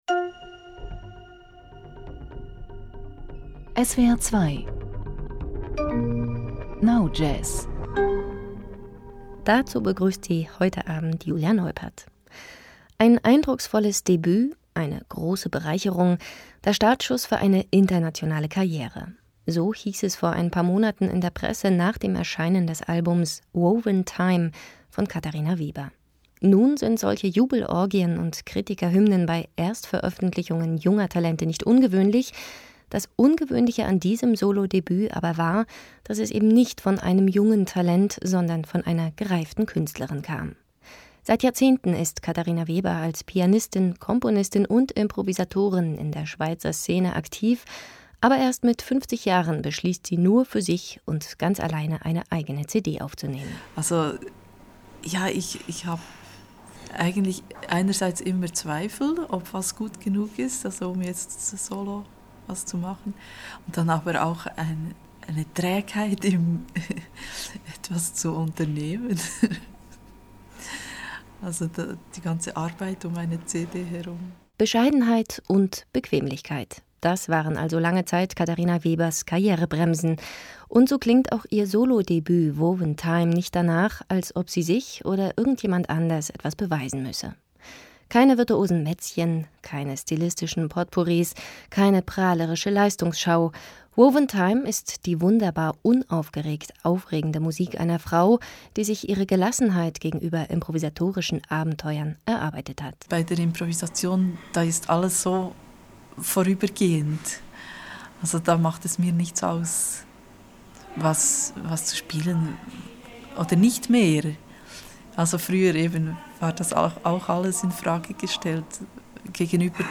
Radiosendung